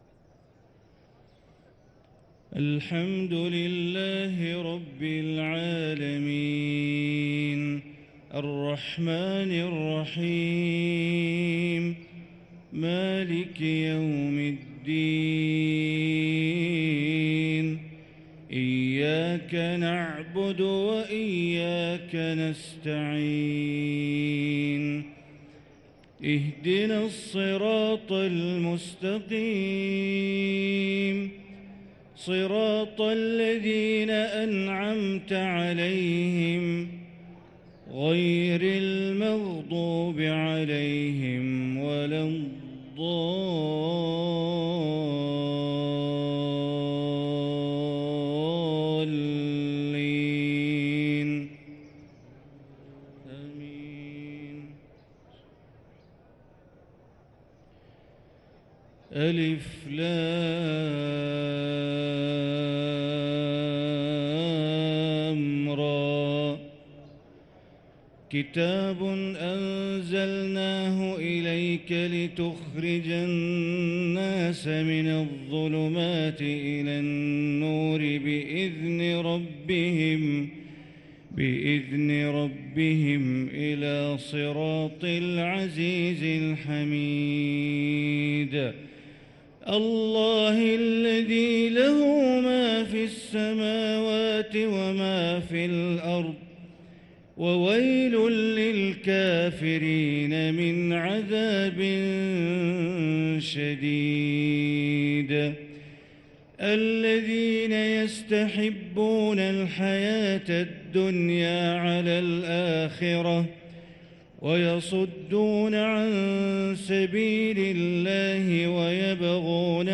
صلاة الفجر للقارئ بندر بليلة 4 جمادي الآخر 1445 هـ
تِلَاوَات الْحَرَمَيْن .